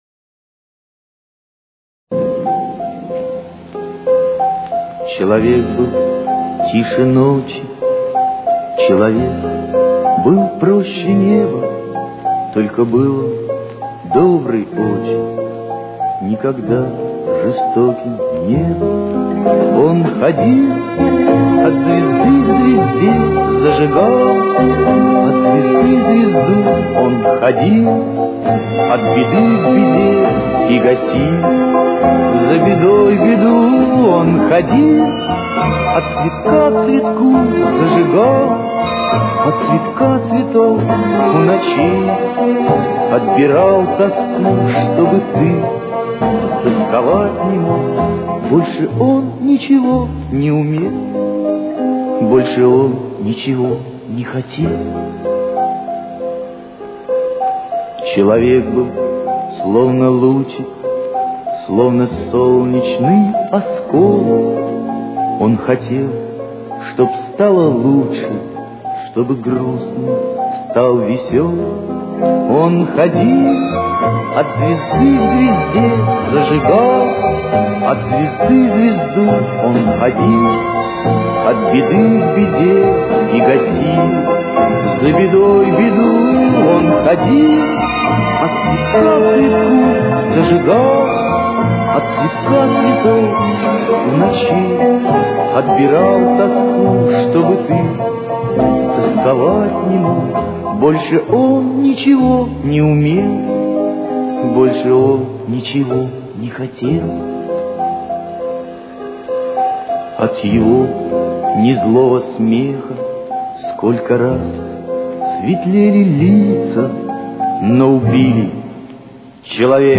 с очень низким качеством (16 – 32 кБит/с)
Фа минор. Темп: 69.